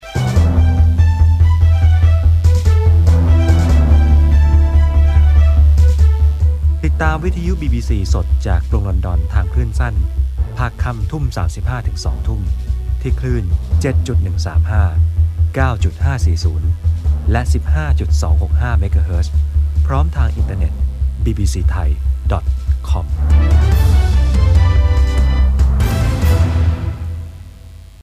Masculino
I am a professional voice over artist.